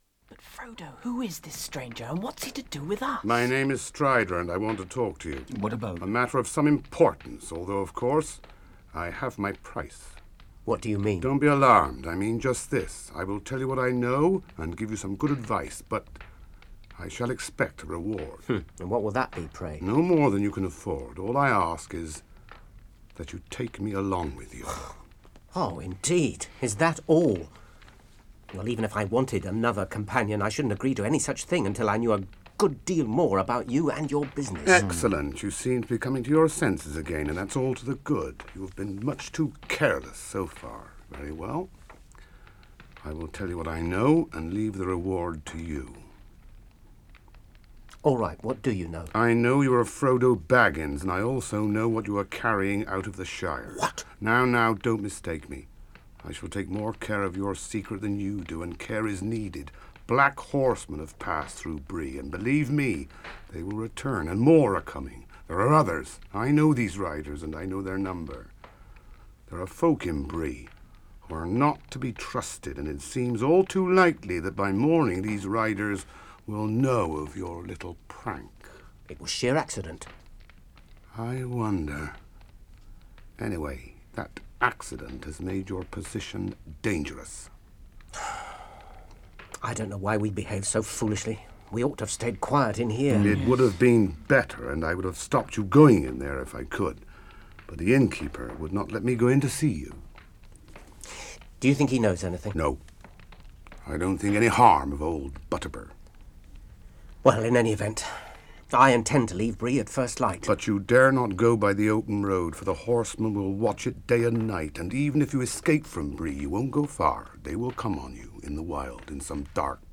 Robert Stephens was… Aragorn.
For many years, this radio adaptation had the voice of Aragorn for me (listen to it in the short snippet below).
He was, for some, an unlikely choice; but for a great many listeners Robert’s powerfully idiosyncratic performance embodied a strong sense of Aragorn’s lost nobility.
aragorn_prancing_pony_talk_with_hobbits.mp3